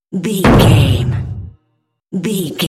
Cinematic drum stab hit trailer
Sound Effects
Atonal
heavy
intense
dark
aggressive